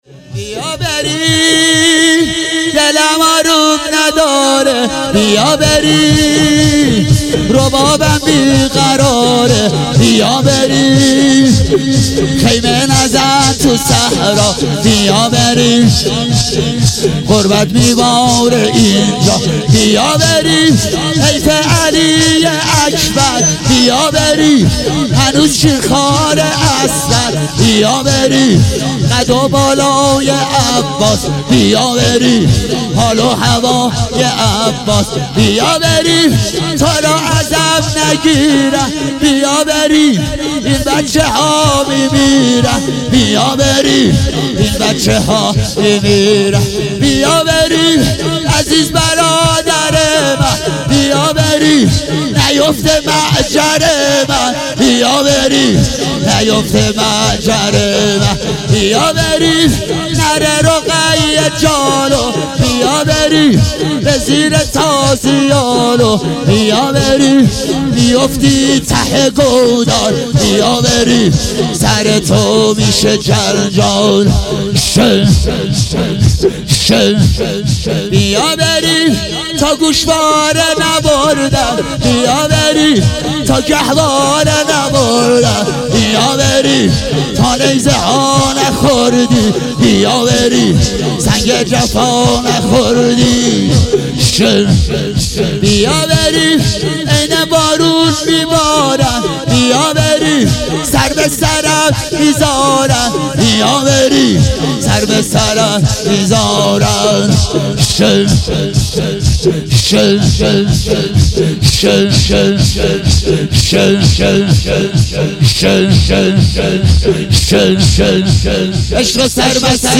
محرم 99 - روز دوم - شور - بیا بریم دلم آروم نداره